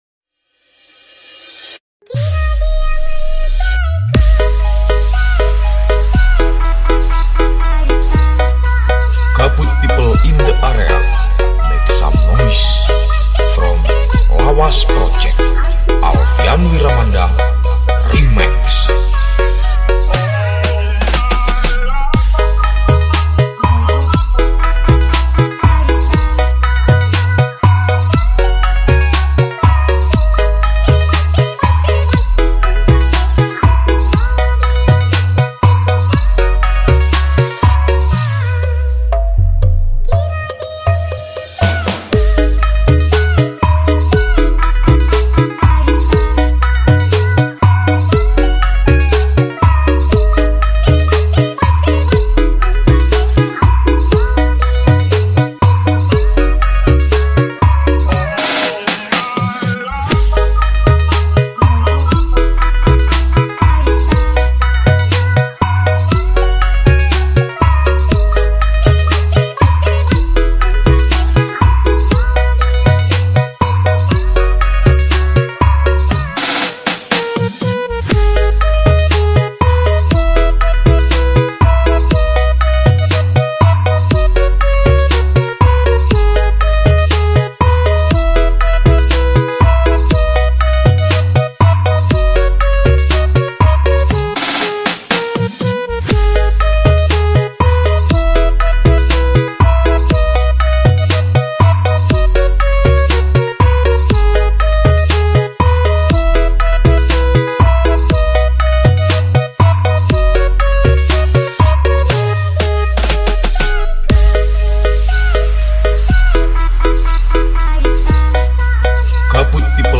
nih cuman cek sound bass saja